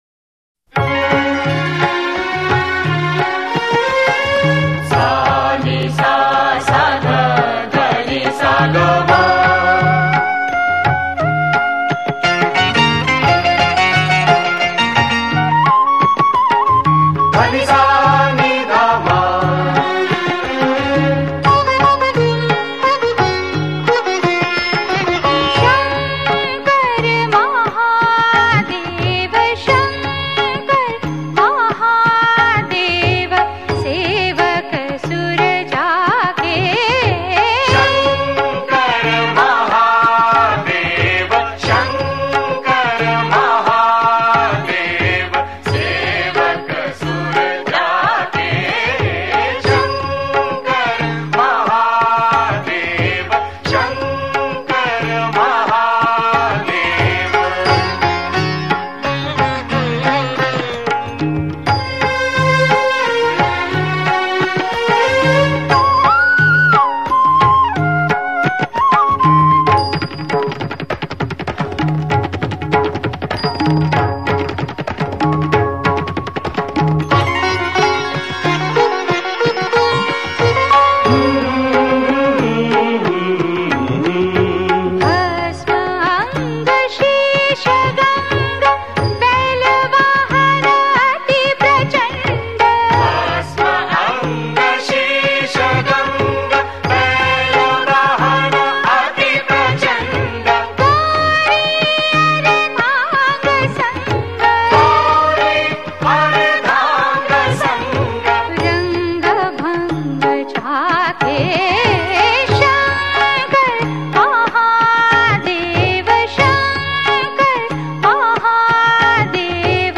Shiv (Bholenath) Single Bhajan